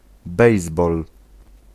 Ääntäminen
UK : IPA : /ˈbeɪs.bɔːl/